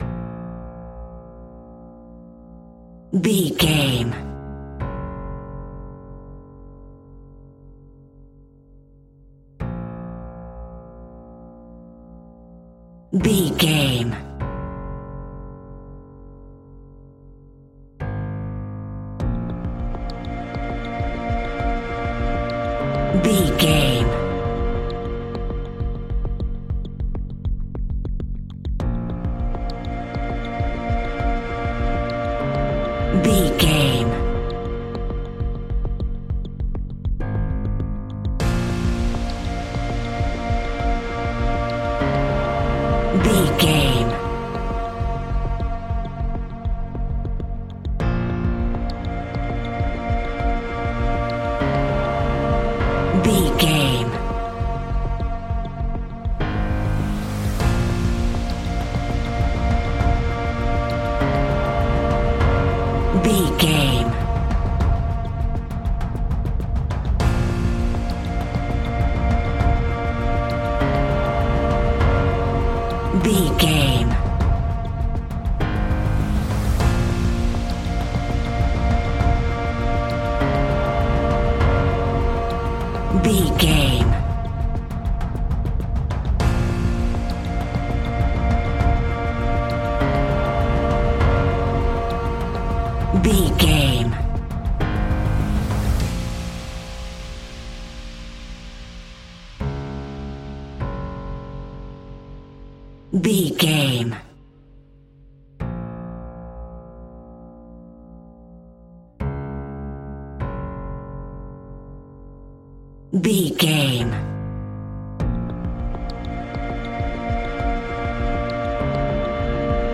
In-crescendo
Thriller
Aeolian/Minor
ominous
dark
haunting
eerie
creepy
horror music
Horror Pads
horror piano
Horror Synths